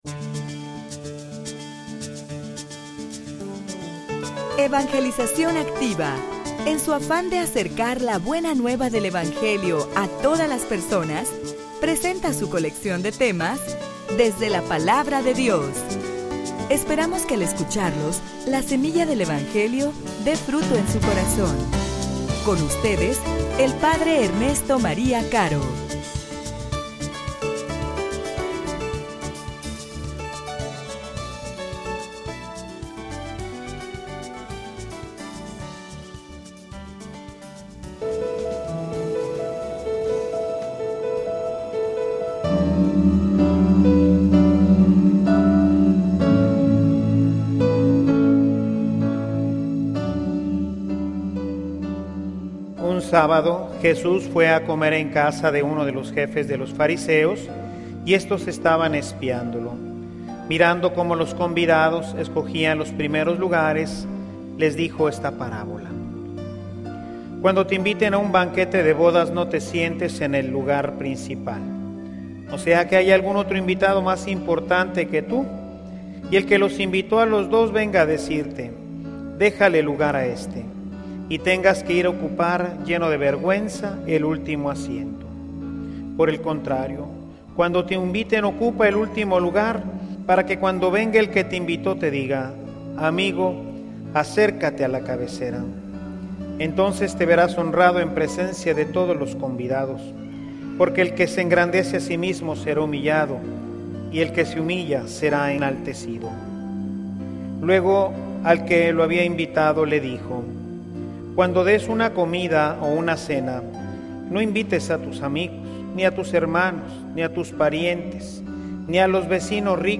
homilia_Dejate_conducir_por_los_criterios_de_Dios.mp3